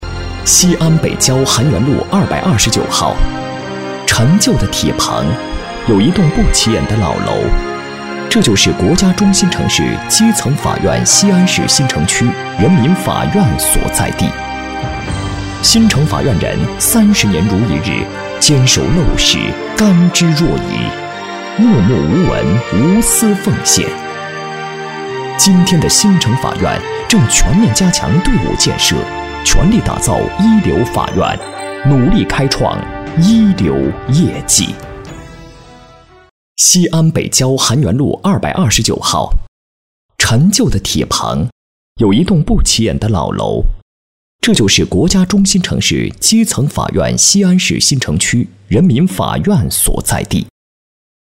稳重磁性 企业专题,人物专题,医疗专题,学校专题,产品解说,警示教育,规划总结配音
磁性稳重男中音，偏年轻声线。